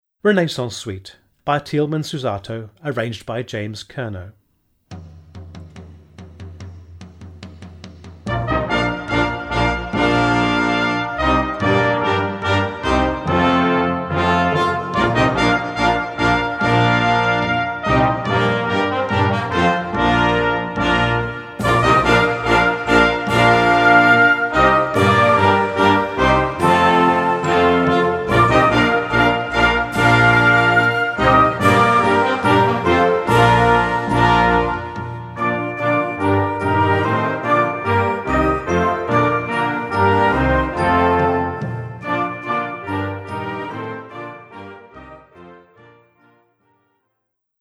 Gattung: Music Works
Besetzung: Blasorchester